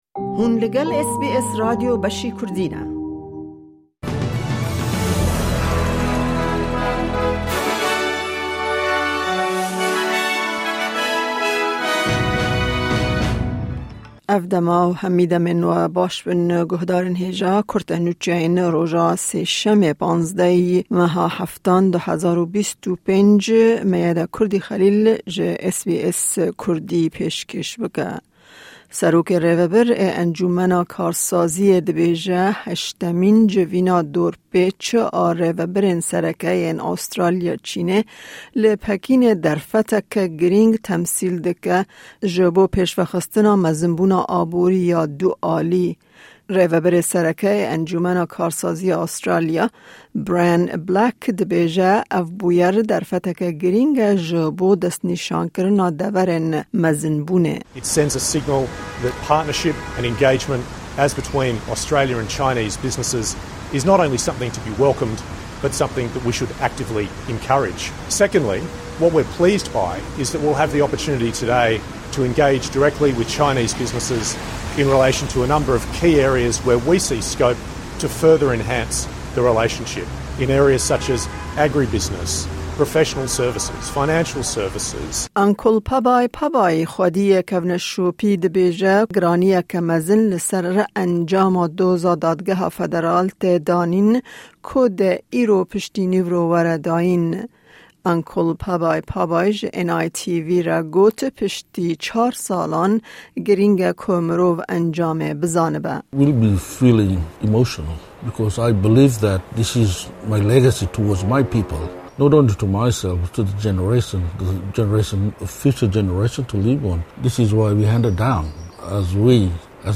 Kurte Nûçeyên roja Sêşemê 15î Tîrmeha 2025